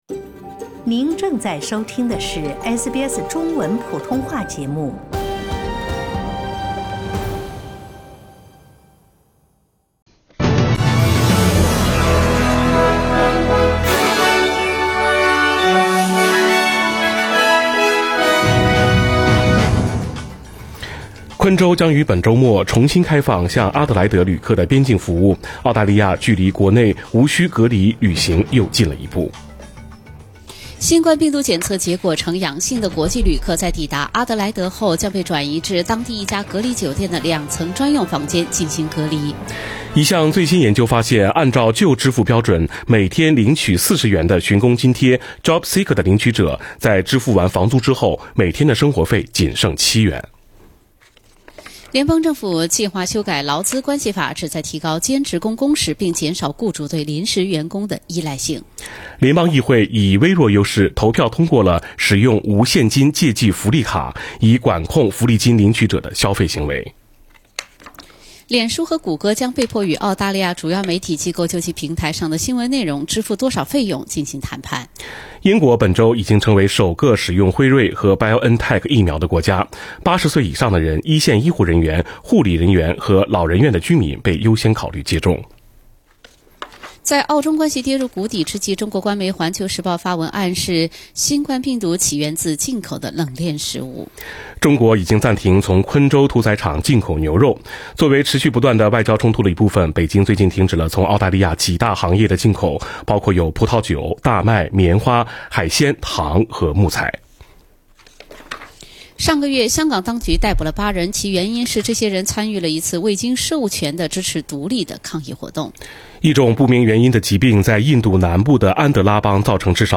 SBS早新聞 （12月8日）